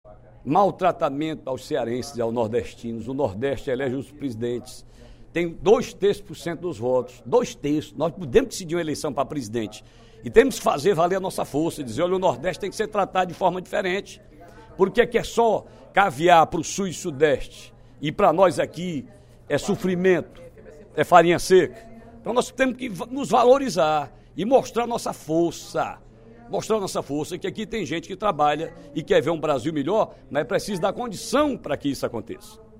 O deputado Ferreira Aragão (PDT) avaliou, durante o primeiro expediente da sessão plenária desta sexta-feira (08/07), o atual momento político do País.